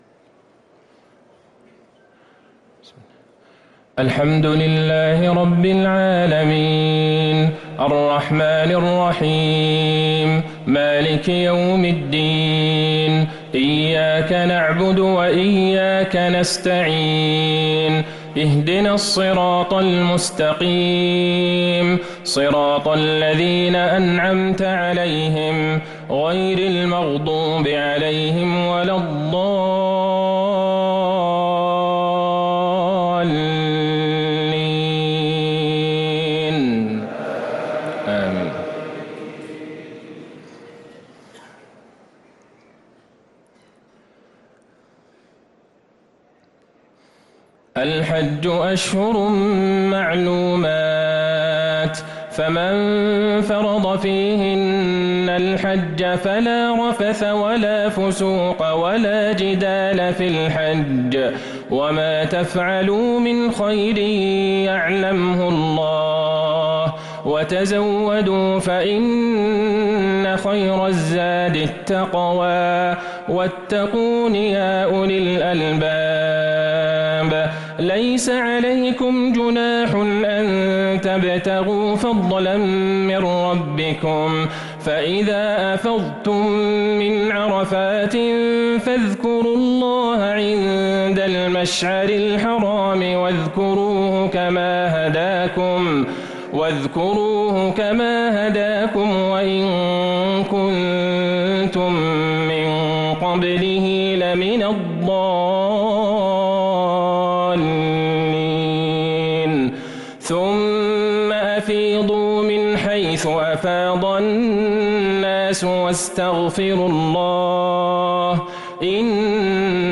صلاة العشاء للقارئ عبدالله البعيجان 15 ذو الحجة 1444 هـ
تِلَاوَات الْحَرَمَيْن .